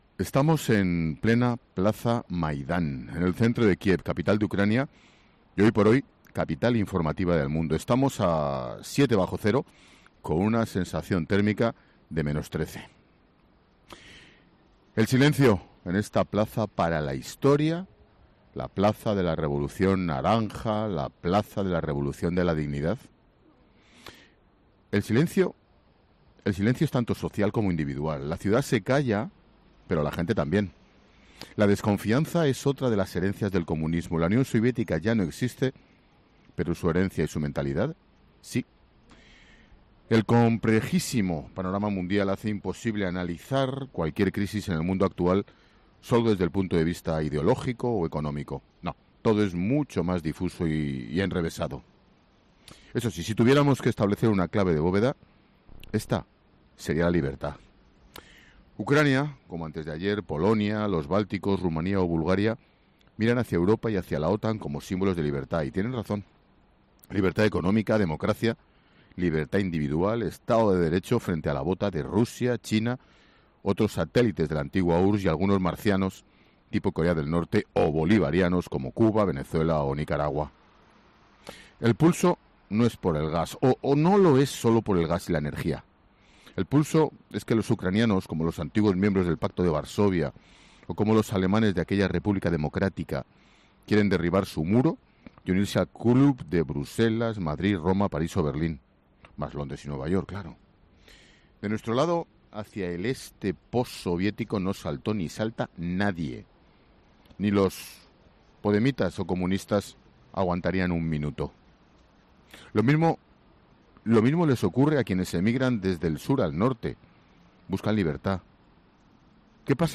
AUDIO: El director de 'La Linterna' analiza desde Kiev las diferencias en el seno de Gobierno por la crisis entre Ucrania y Rusia
Monólogo de Expósito